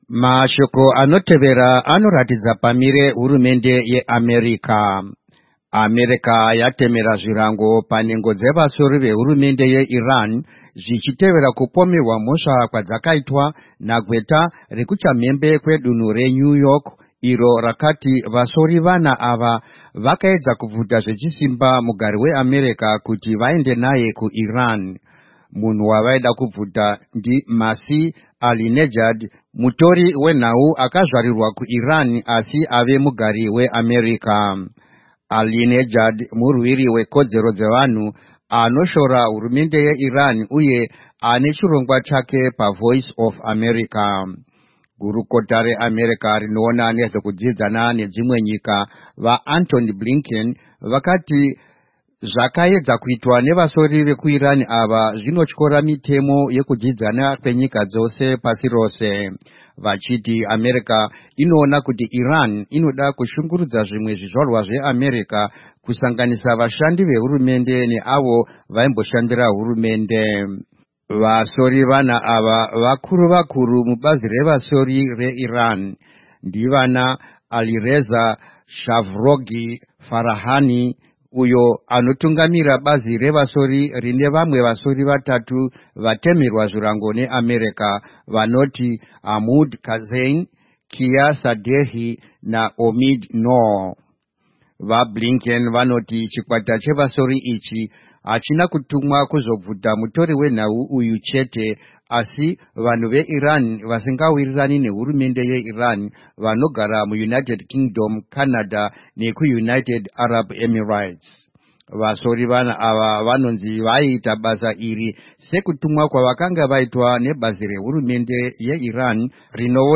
Editorial: US imposes Sanctions on Four Iranian Spies